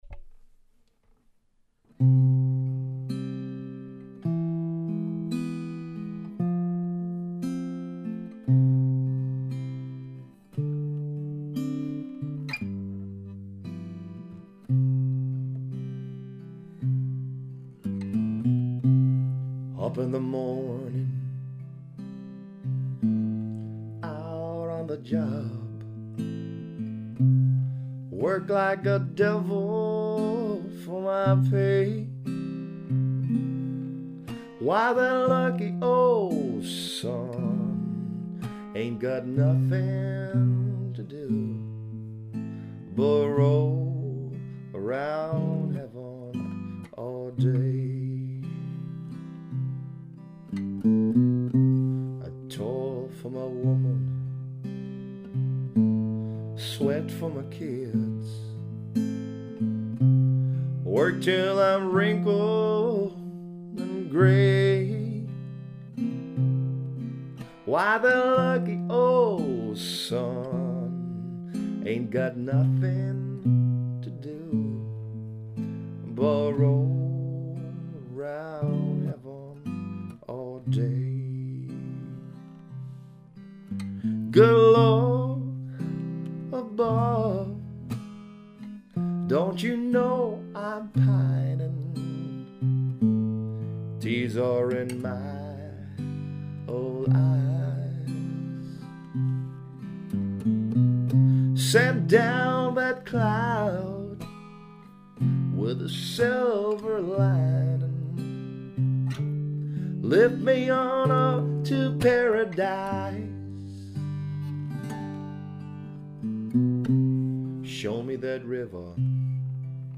Demo Version (2012)
Gesang, Gitarre